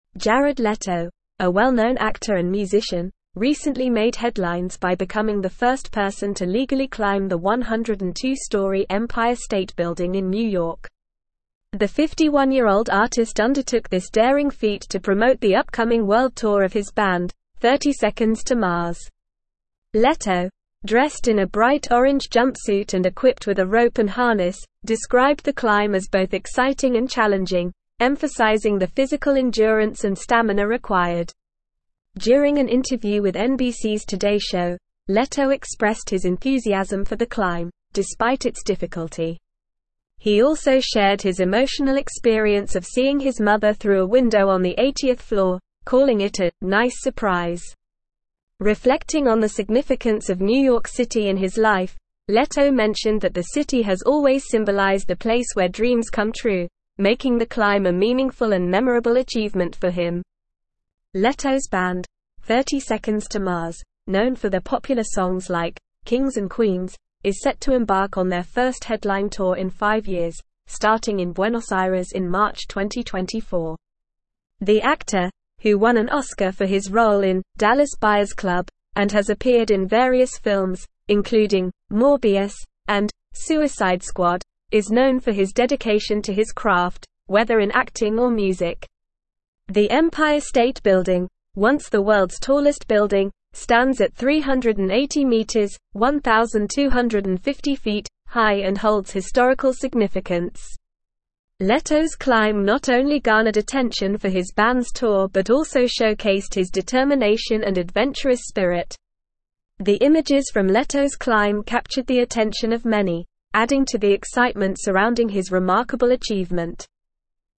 Normal
English-Newsroom-Advanced-NORMAL-Reading-Jared-Leto-Scales-Empire-State-Building-for-Band.mp3